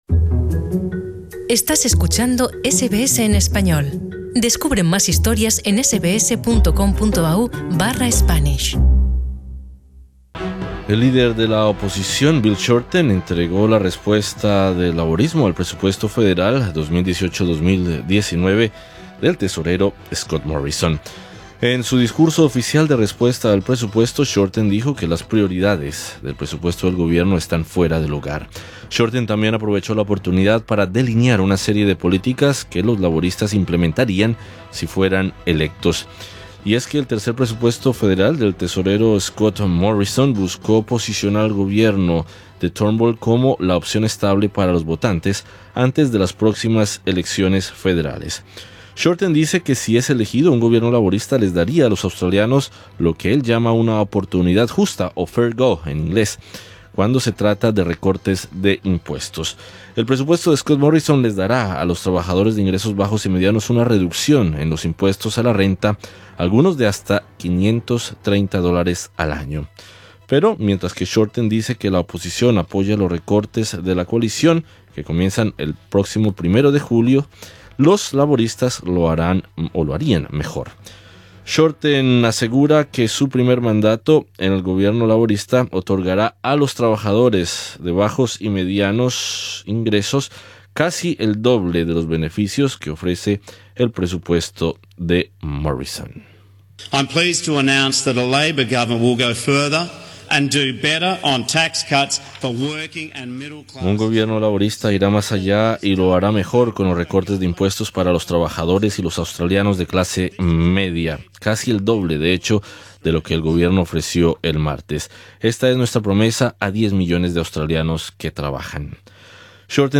Entrevista con el economista en Melbourne